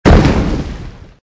flak.wav